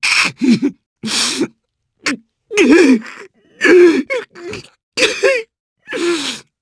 Gladi-Vox_Sad_jp.wav